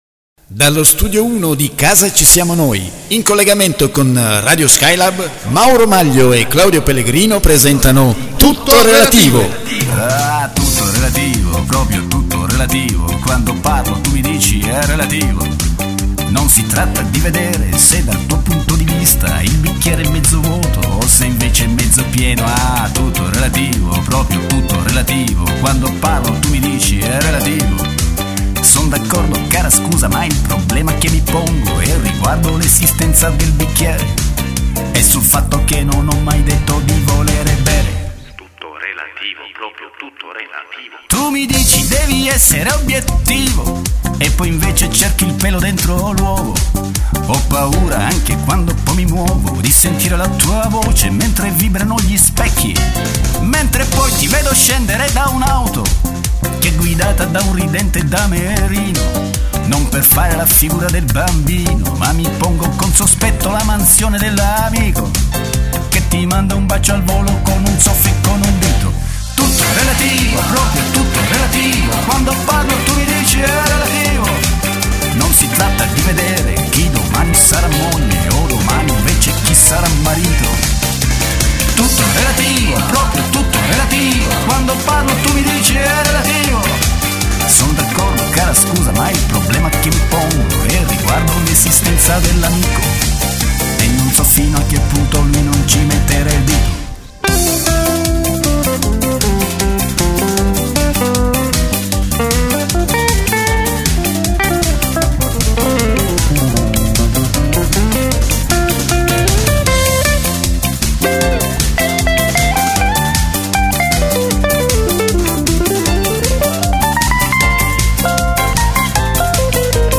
sigla dell'omonimo programma